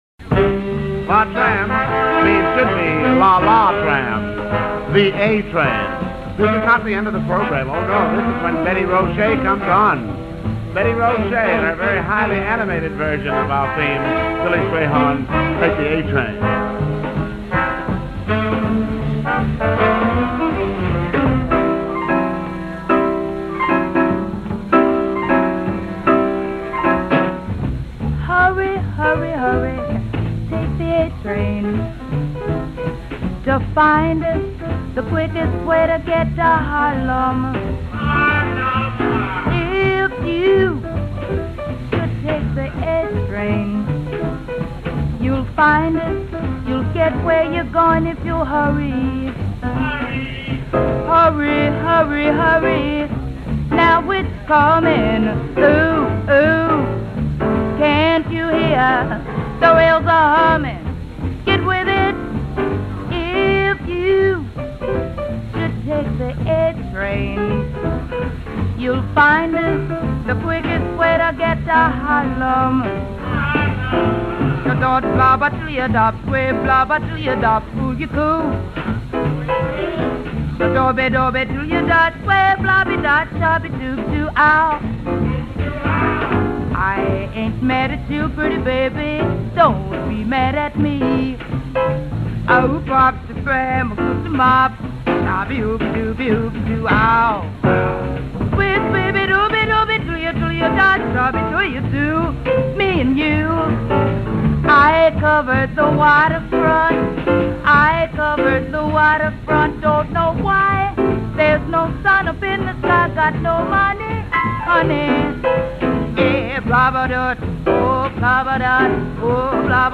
певица